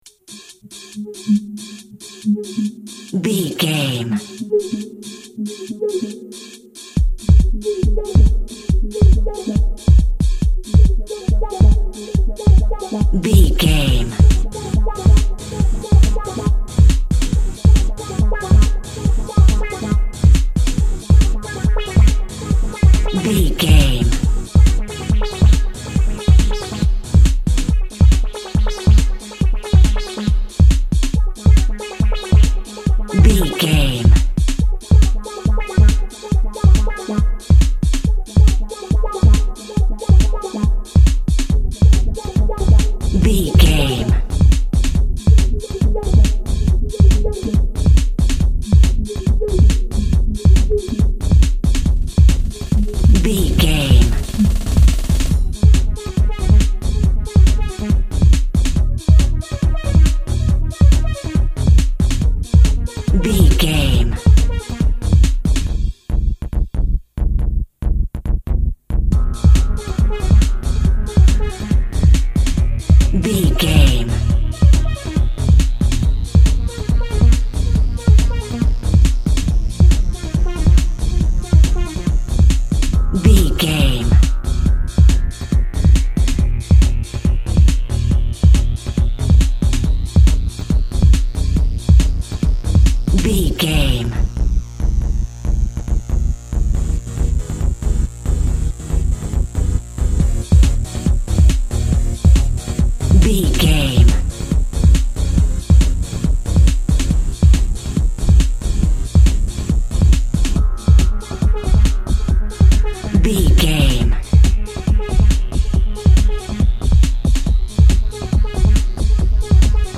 Aeolian/Minor
G#
Fast
futuristic
hypnotic
industrial
mechanical
electronic
energetic
synth lead
synth bass
Electronic drums
Synth pads